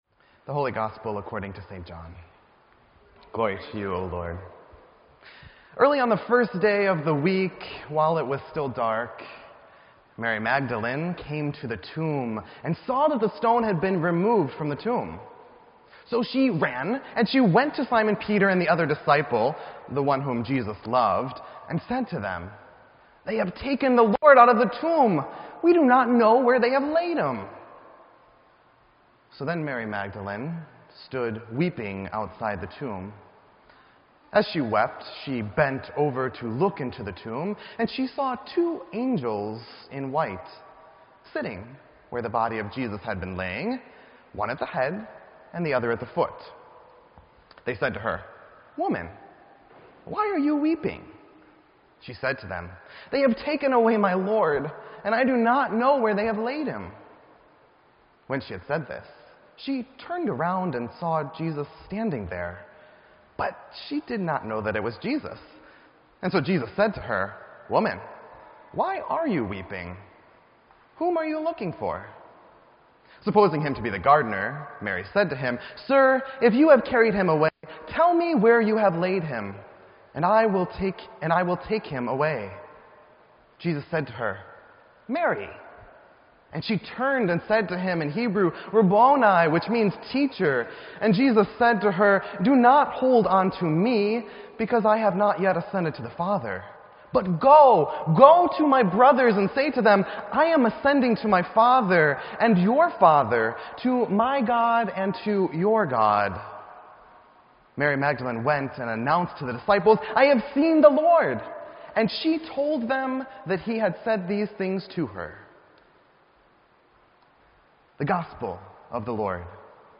Sermon_7_17_16.mp3